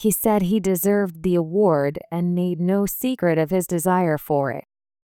音声の連結が強く、/meɪnoʊˈsiːkrɪɾəv/のようにつながって聞こえます。